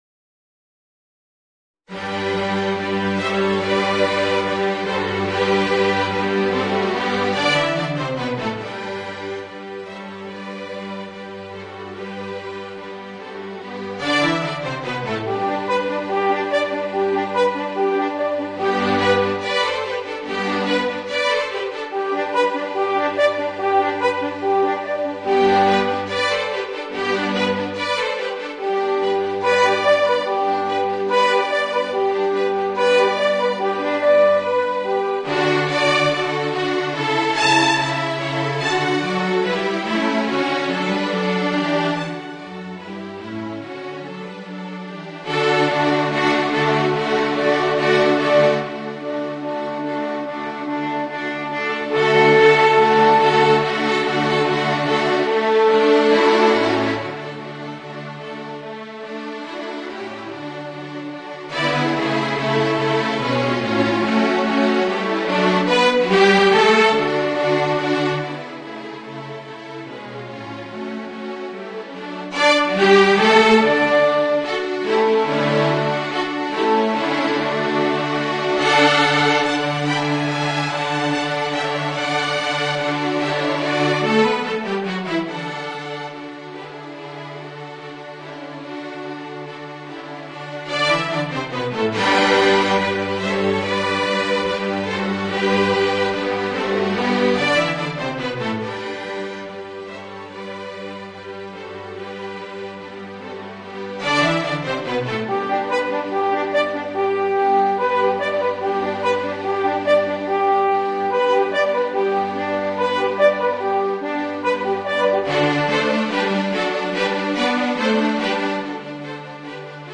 Voicing: Alphorn and String Orchestra